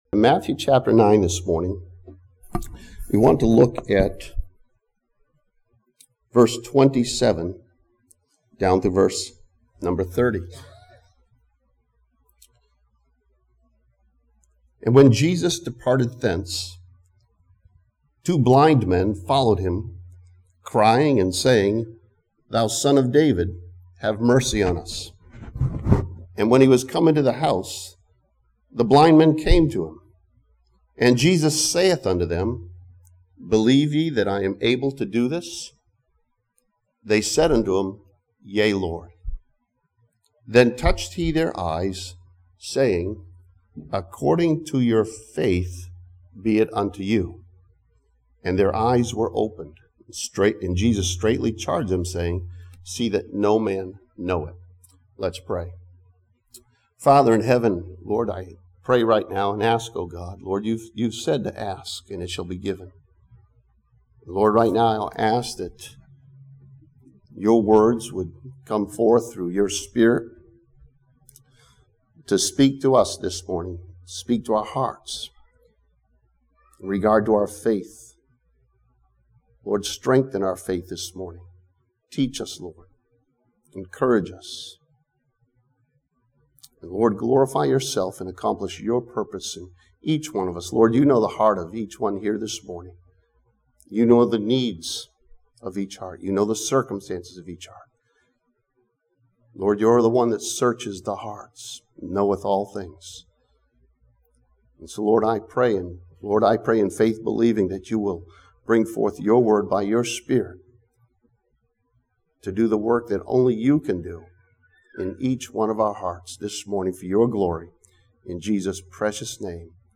This sermon from Matthew chapter 9 studies the topic of faith and its reward for believers.